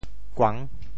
潮州发音 潮州 guang6 文 kuang1
kuang6.mp3